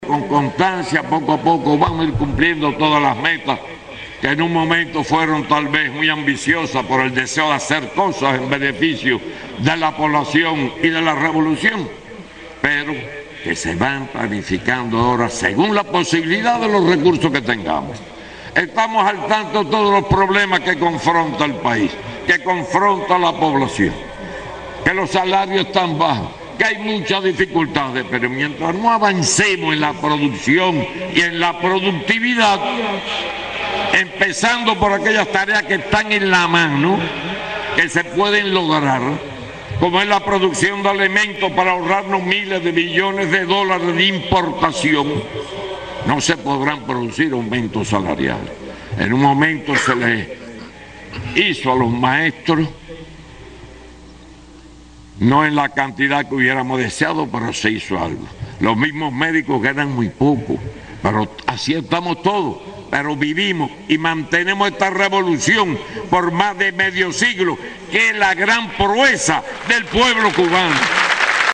Raúl Castro el 26 de julio en Guantánamo: "Así estamos todos"